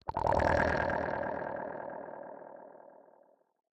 Minecraft Version Minecraft Version latest Latest Release | Latest Snapshot latest / assets / minecraft / sounds / block / conduit / attack3.ogg Compare With Compare With Latest Release | Latest Snapshot
attack3.ogg